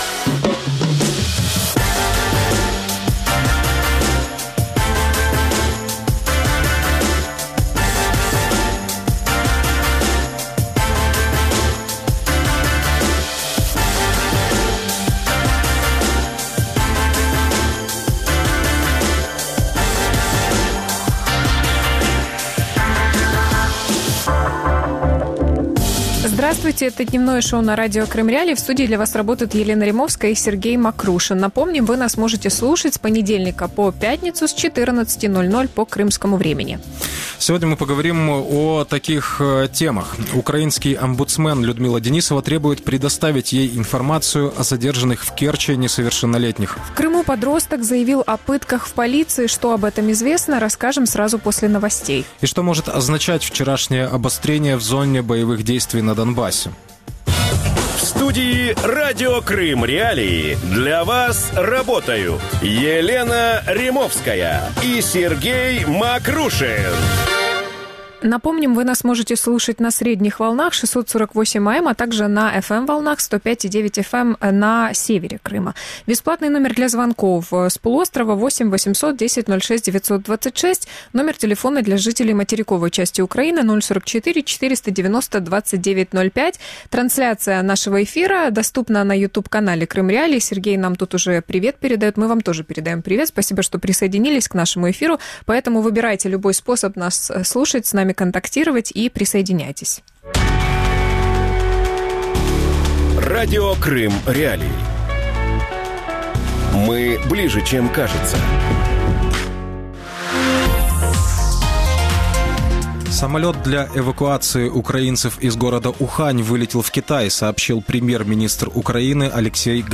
Пытки в Крыму «помолодели» | Дневное ток-шоу